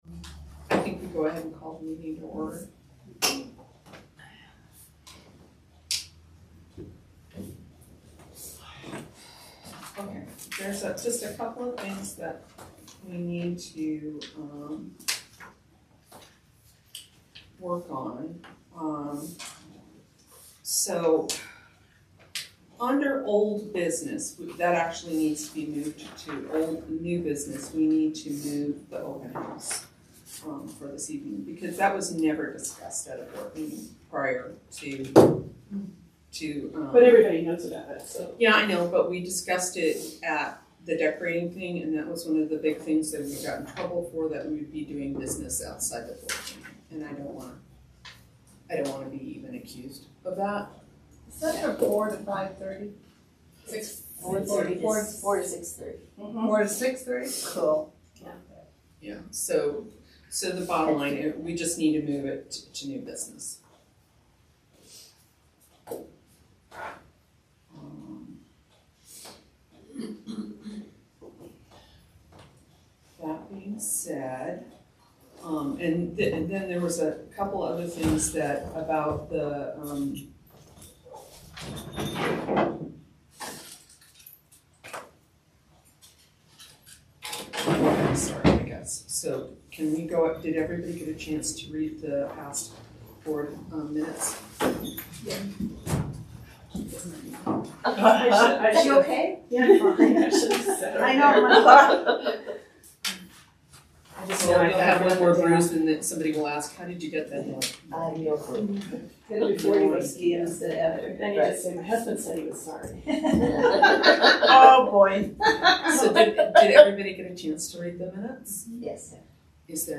Callahan Advisory Board Meeting - December 2022